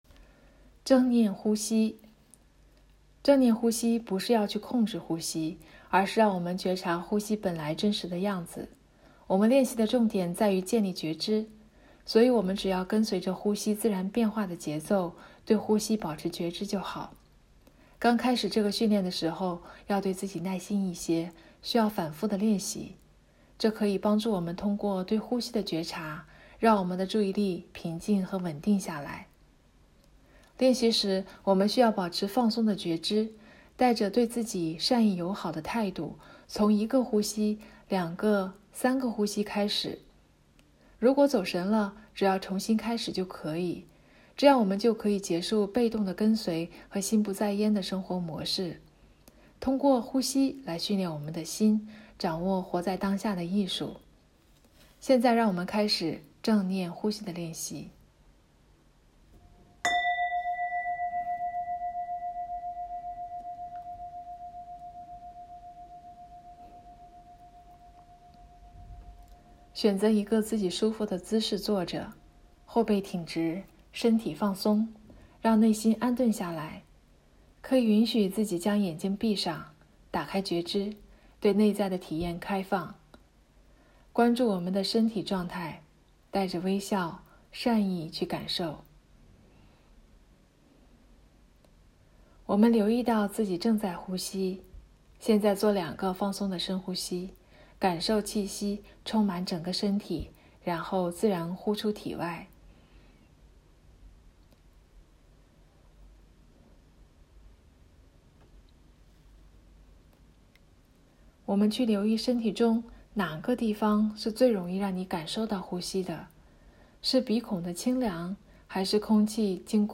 正念练习内容音频 – 呼吸练习